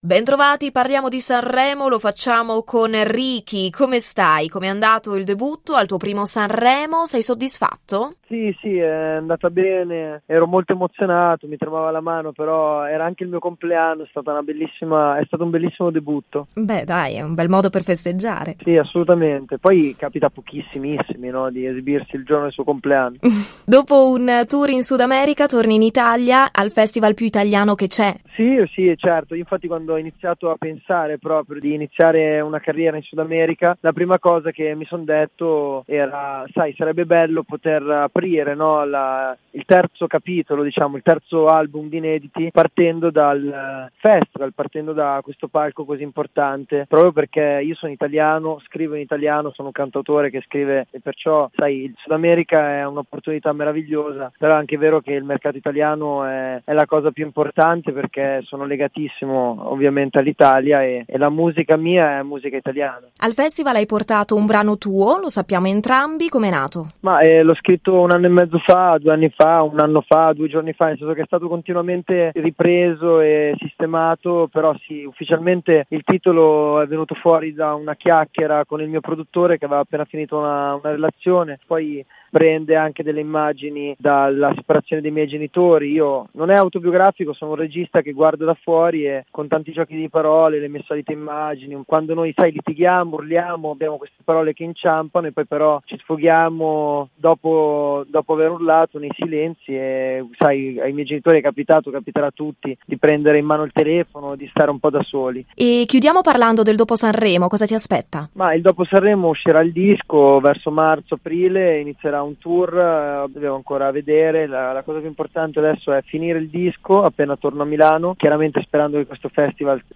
SANREMO 2020: RADIO PICO INTERVISTA RIKI